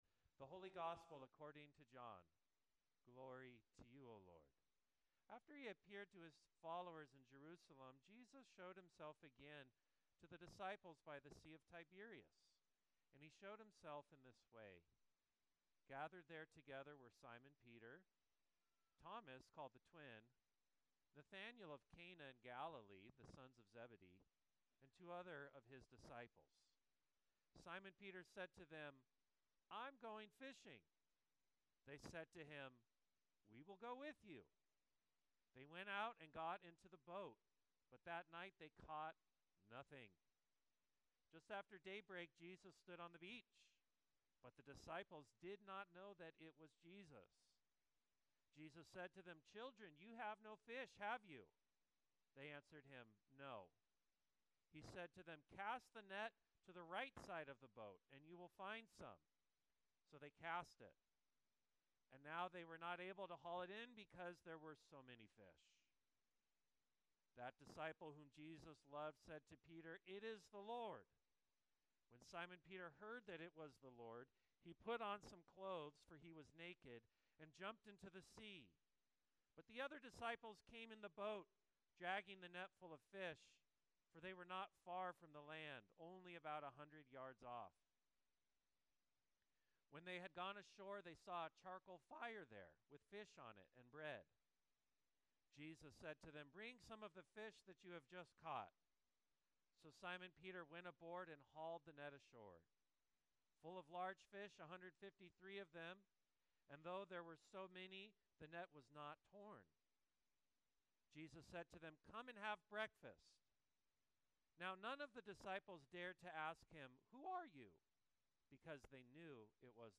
Sermon 05.04.25